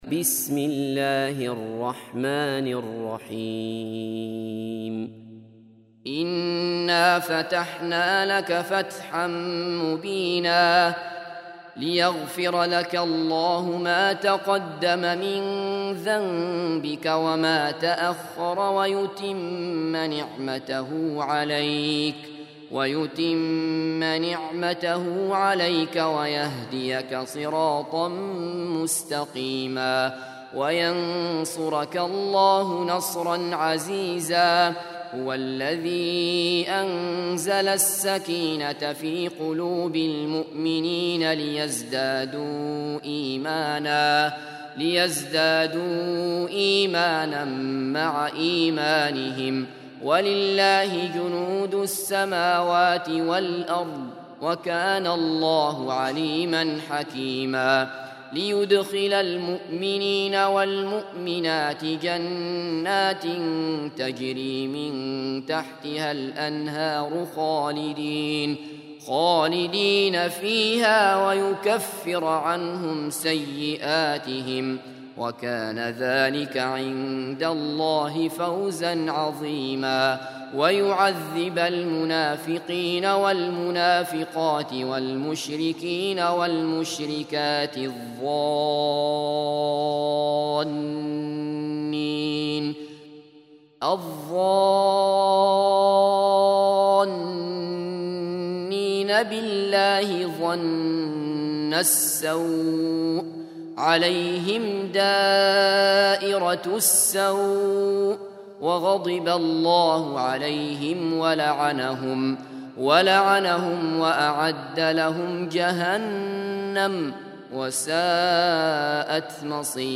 48. Surah Al-Fath سورة الفتح Audio Quran Tarteel Recitation
Surah Repeating تكرار السورة Download Surah حمّل السورة Reciting Murattalah Audio for 48.